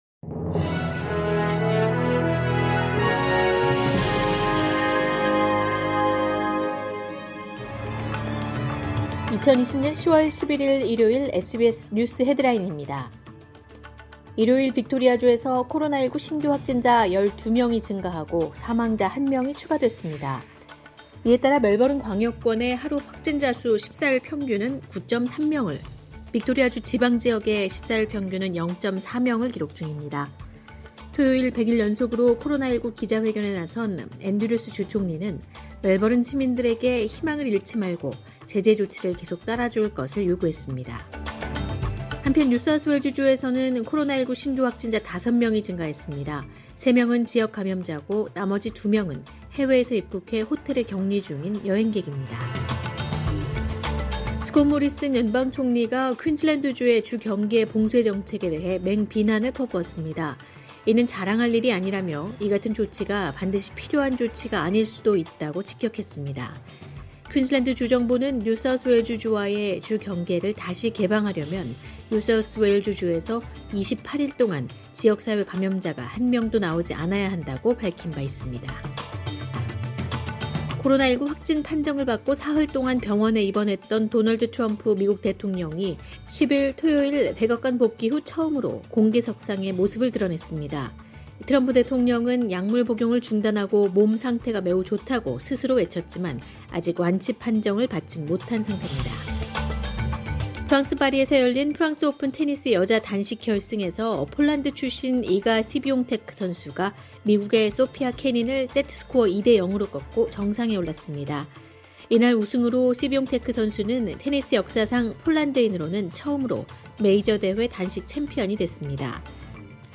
2020년 10월 11일 일요일 SBS 뉴스 헤드라인입니다.